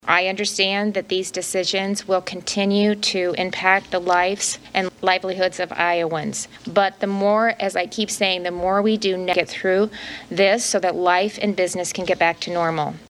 REYNOLDS SAYS SHE IS AWARE OF THE ECONOMIC IMPACT OF HER NEW MANDATES: